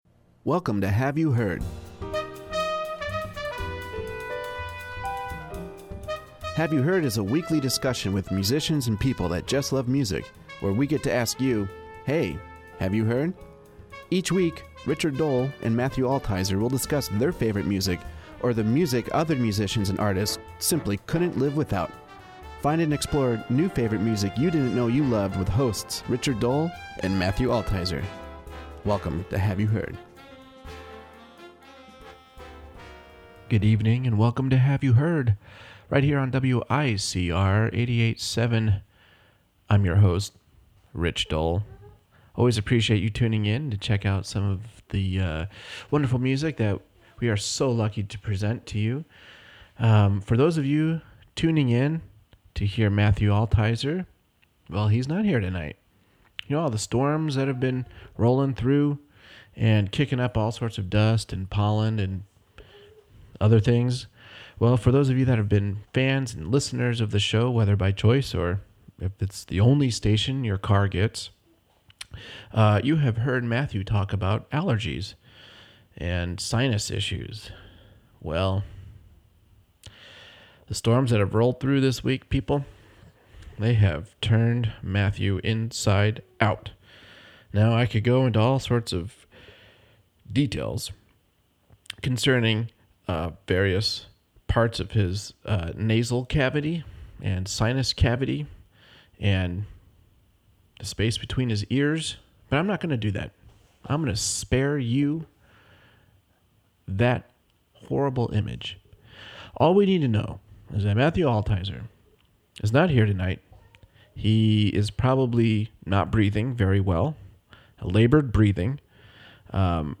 It truly is deep, beautiful, soulful, inviting and comforting!
Interview